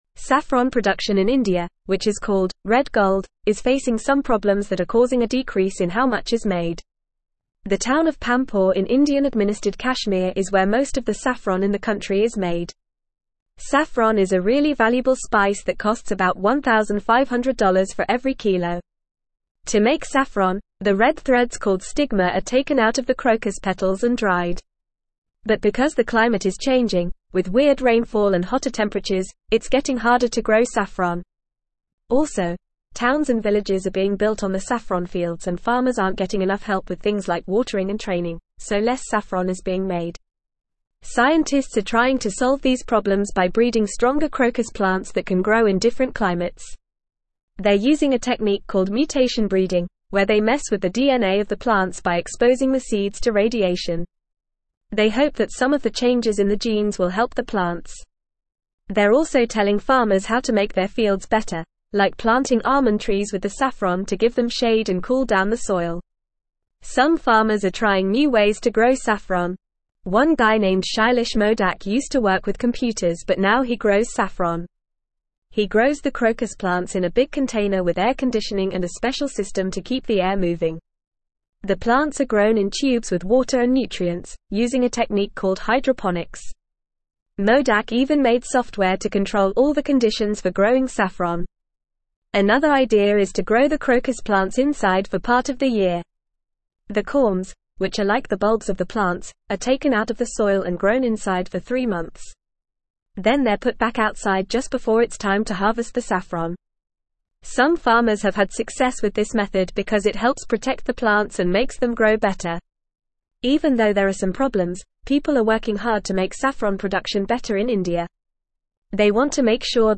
Fast
English-Newsroom-Upper-Intermediate-FAST-Reading-Saffron-production-in-India-challenges-and-solutions.mp3